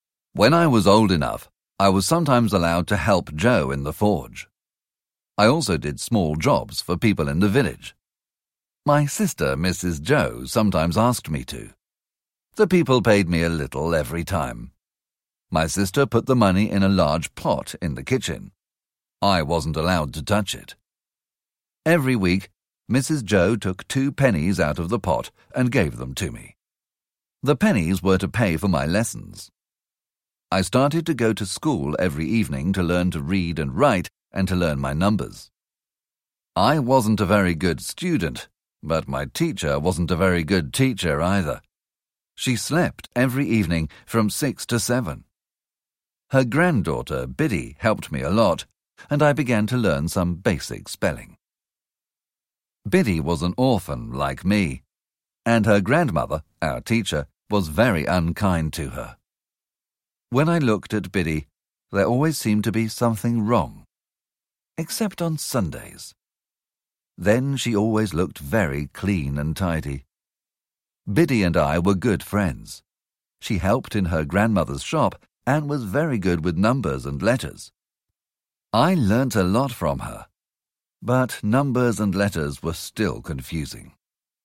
Great Expectations (EN) audiokniha
Ukázka z knihy